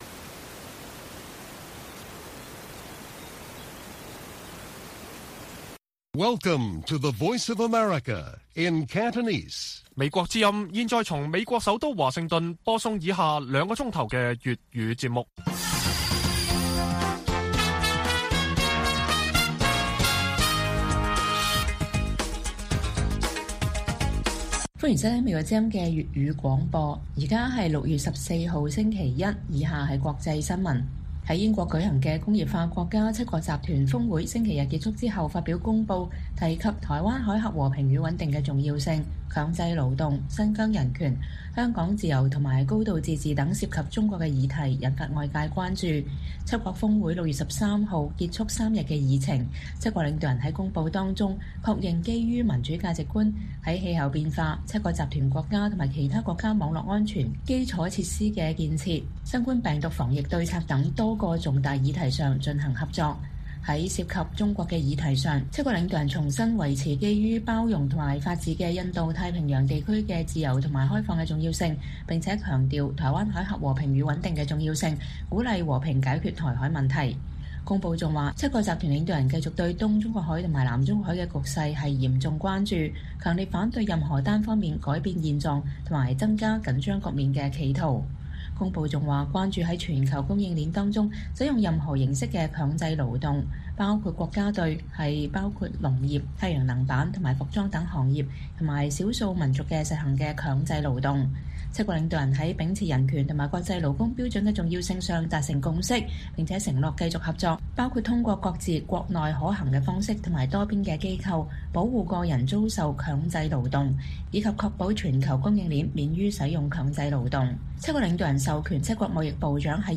粵語新聞 晚上9-10點: 七國集團峰會公報多處提到中國